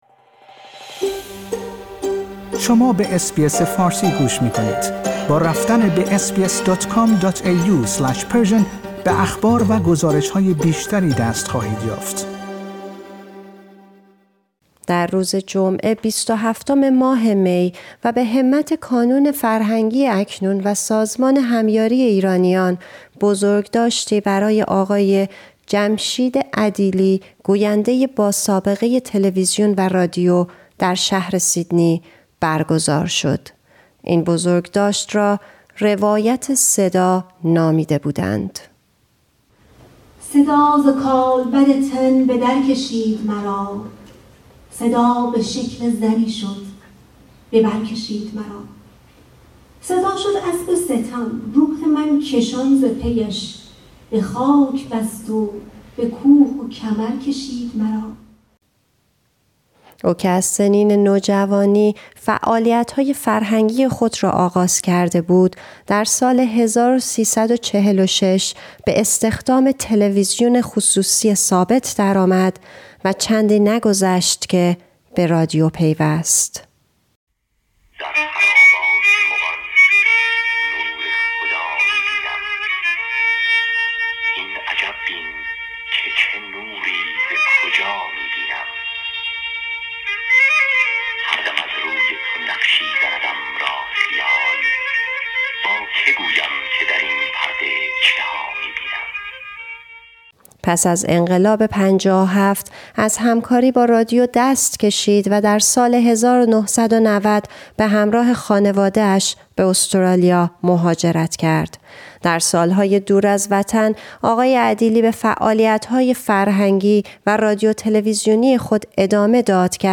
اس بی اس فارسی، از این بزرگداشت گزارشی تهیه کرده که توجه شما را به آن جلب می کنیم.